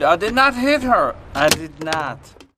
death6.wav